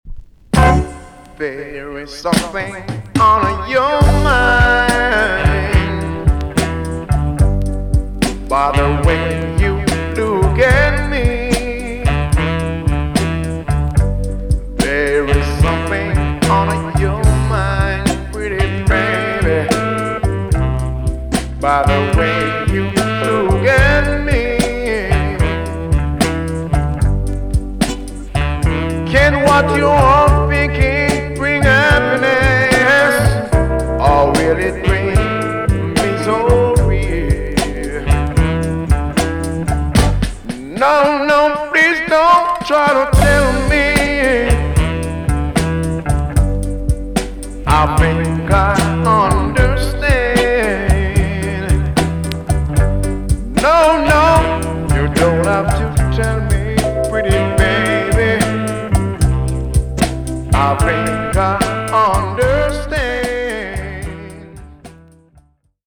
TOP >LP >VINTAGE , OLDIES , REGGAE
B.SIDE EX- 音はキレイです。